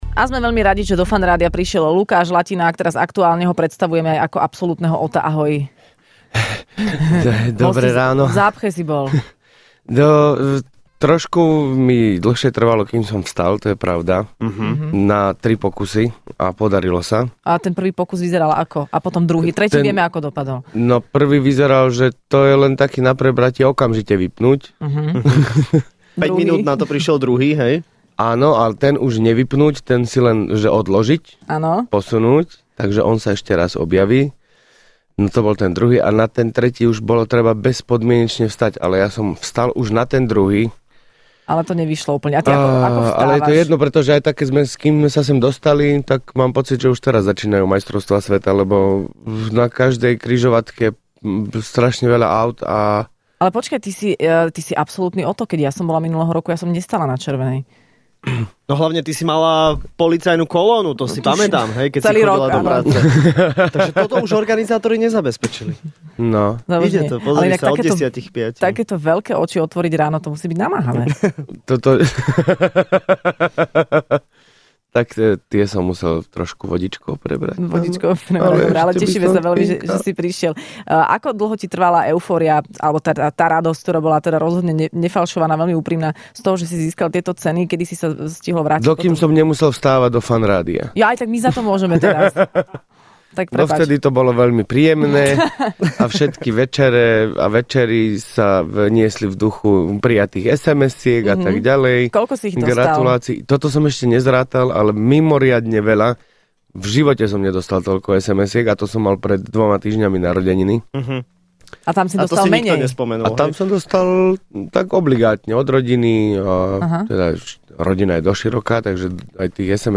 Hosťom v Rannej šou bol absolútny víťaz ocenenia OTO Lukáš Latinák.